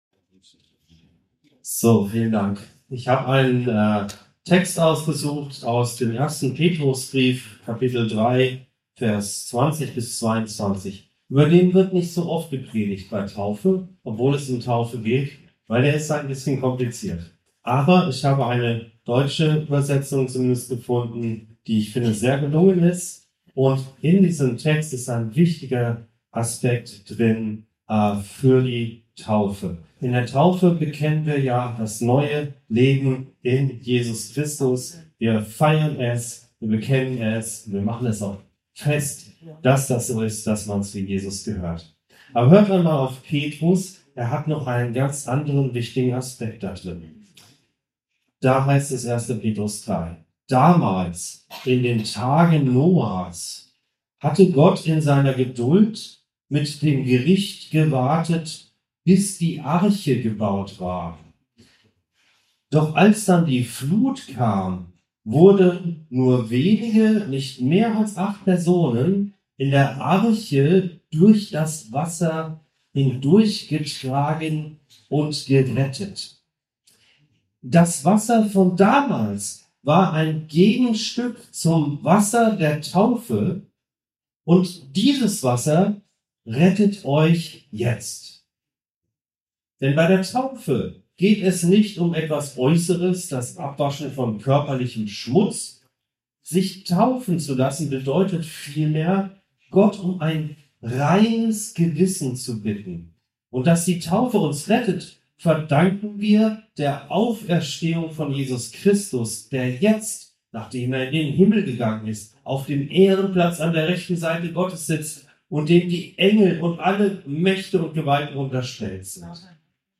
Die Kraft eines guten Gewissens 1 Petr 3,20-22 ~ Anskar-Kirche Hamburg- Predigten Podcast